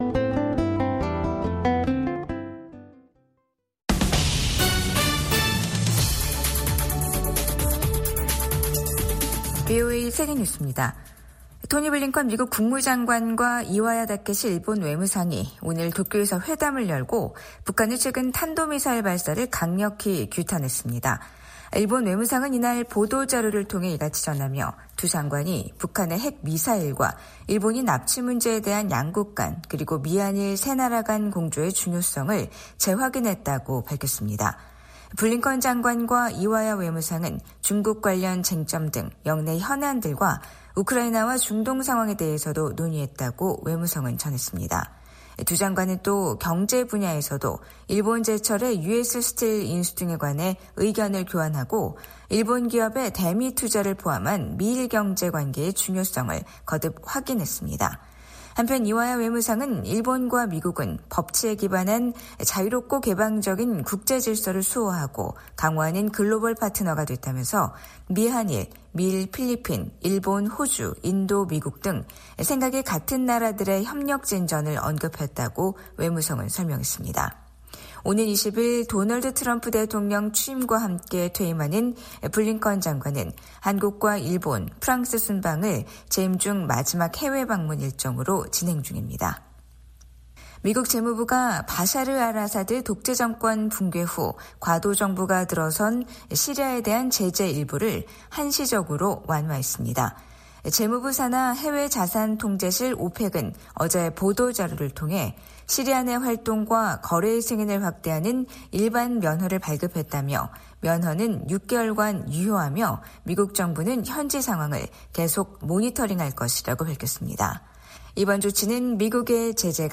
VOA 한국어 간판 뉴스 프로그램 '뉴스 투데이', 2025년 1월 7일 2부 방송입니다. 북한은 어제(6일) 신형 극초음속 중장거리 탄도미사일 시험발사에 성공했다며 누구도 대응할 수 없는 무기체계라고 주장했습니다. 미국과 한국의 외교장관이 북한의 탄도미사일 발사를 강력히 규탄했습니다.